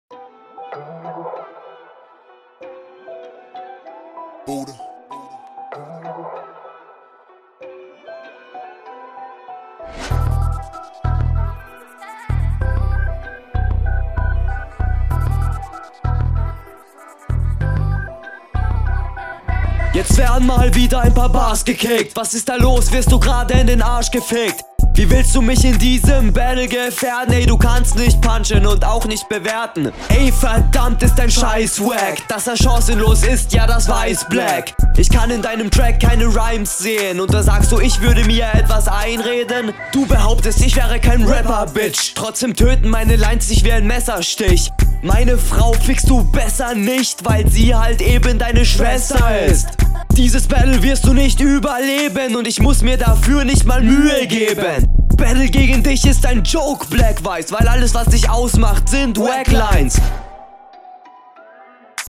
Auch hier, finde ich den Flow und die Mische ganz nice, jedoch minimal schlechter, als …
Du rappst deutlich unroutinierter als dein Gegner, du hast Probleme mit der Silbenanzahl in den …